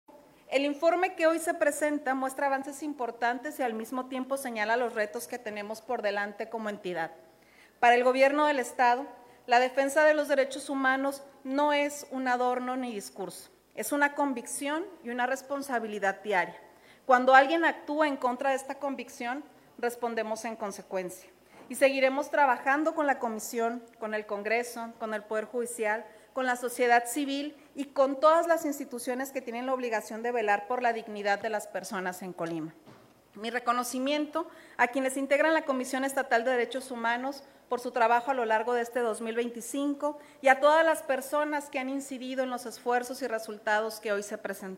++La Gobernadora del Estado acudió al Recinto Legislativo para escuchar el Informe Anual de la CDHEC